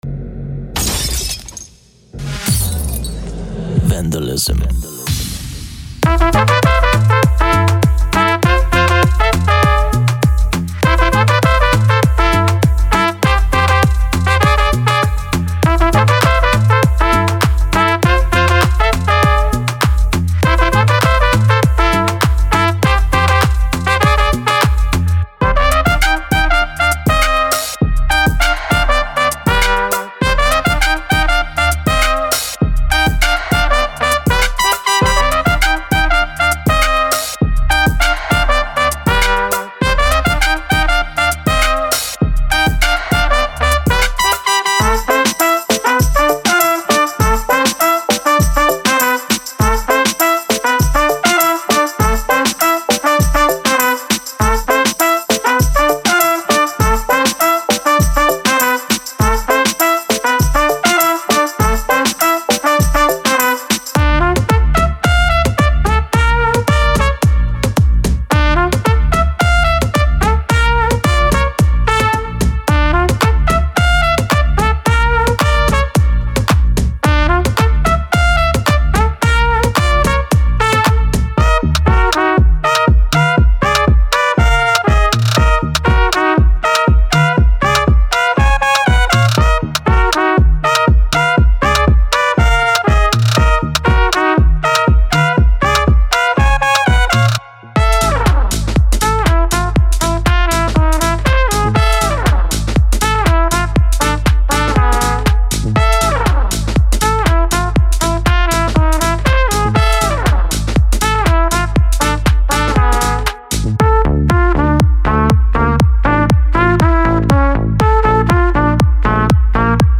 Ultra_Pop_Trumpets.mp3